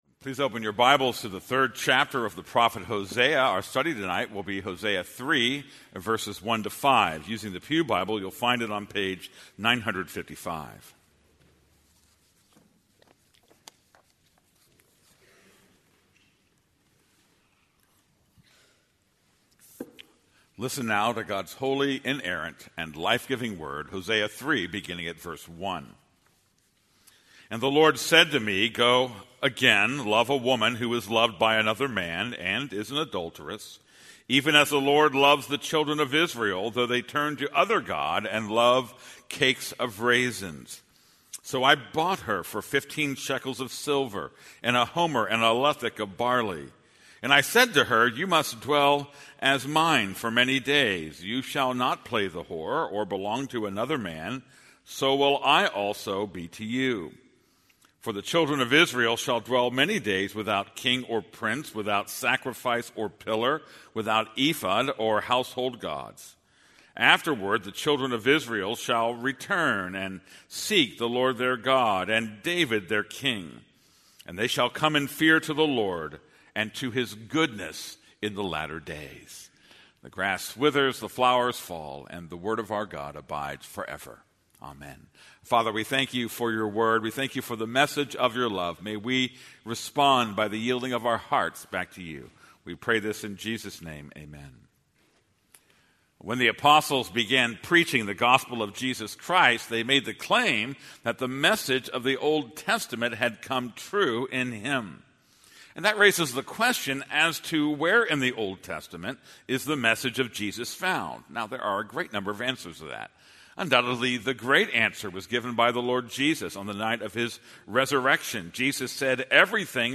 This is a sermon on Hosea 3:1-5.